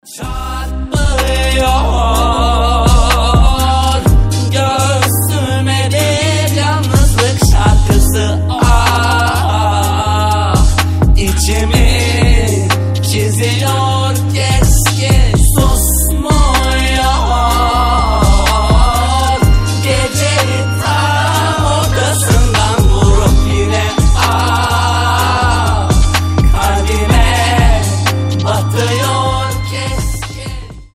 грустные
медленные